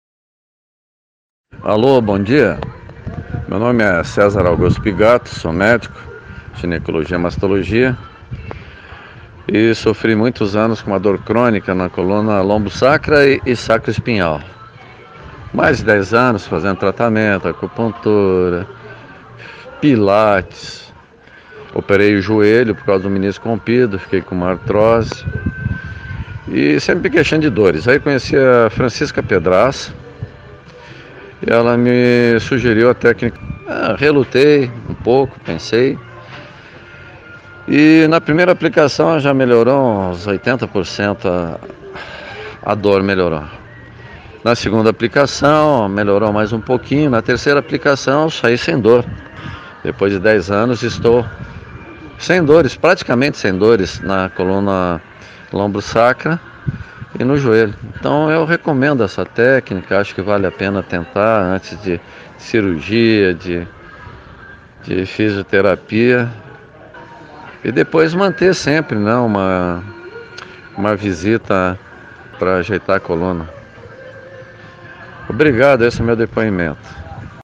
Veja o que alunos e pacientes tem a dizer.